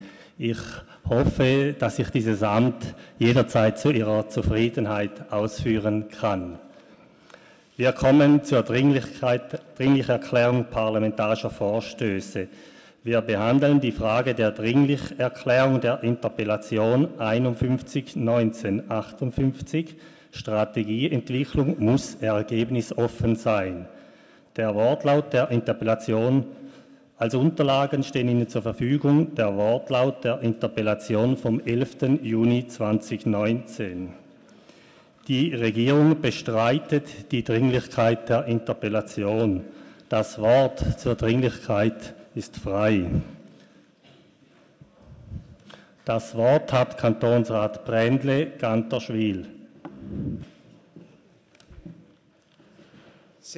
Vizepräsident des Kantonsrates: Die Regierung bestreitet die Dringlichkeit.
Session des Kantonsrates vom 11. bis 13. Juni 2019